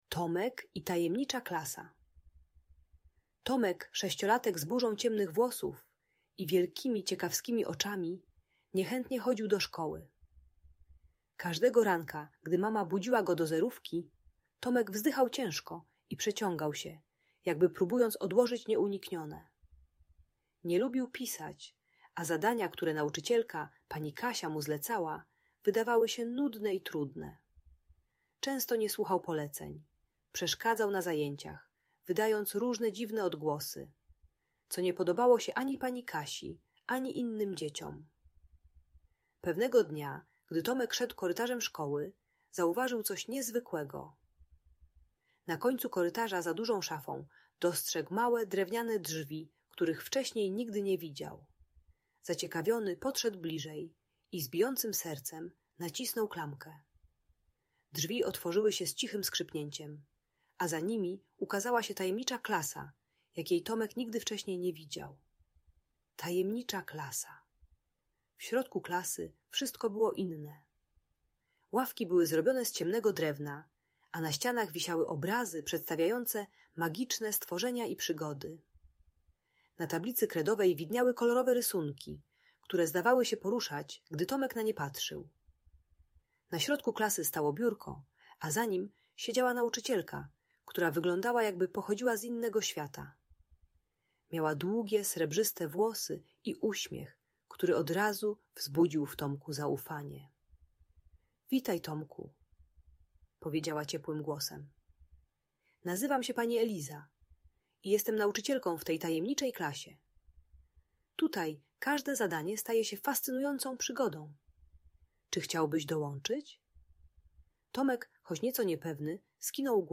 Tomek i Tajemnicza Klasa - Audiobajka